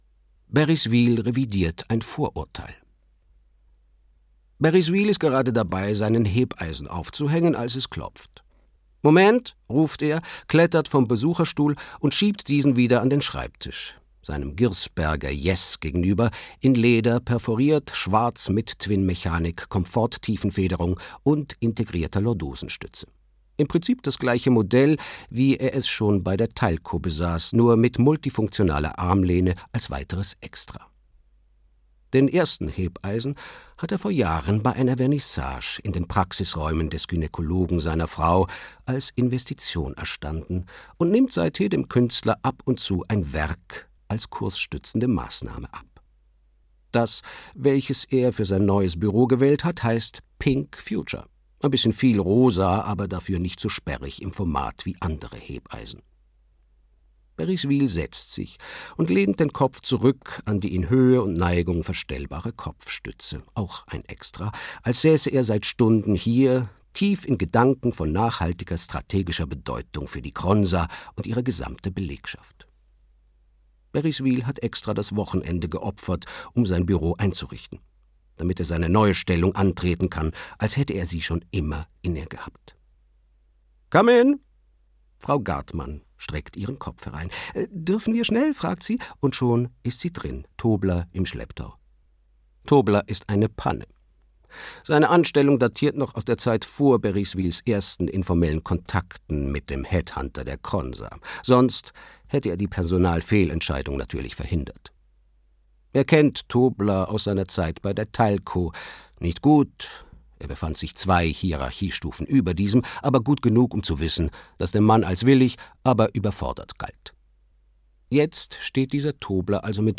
Alles im Griff - Martin Suter - Hörbuch